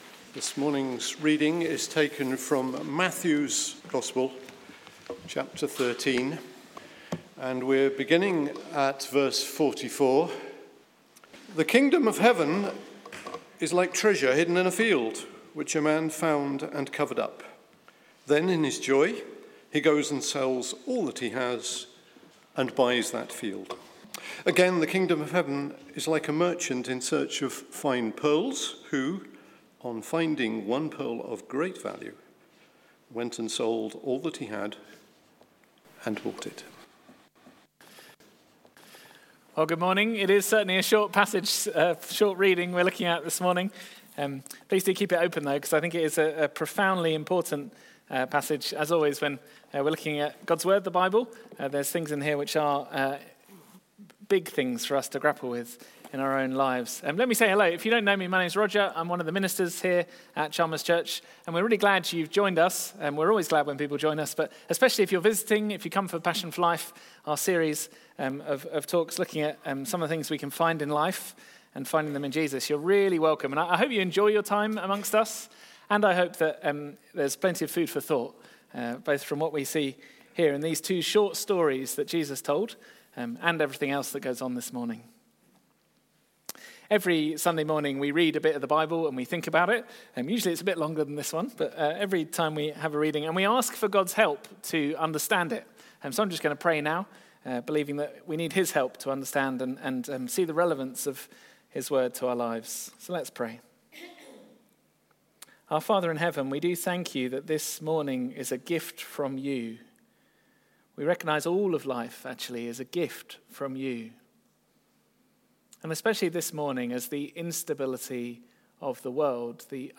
Sermons from Chalmers Church Edinburgh